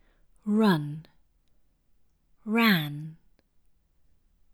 Words like “run” and “ran” frequently merge for Italian speakers, whereas for British speakers of English, these words would be contrastive.
Open the mouth wide with a front tongue for “a” and relax the mouth fully for the “u” here.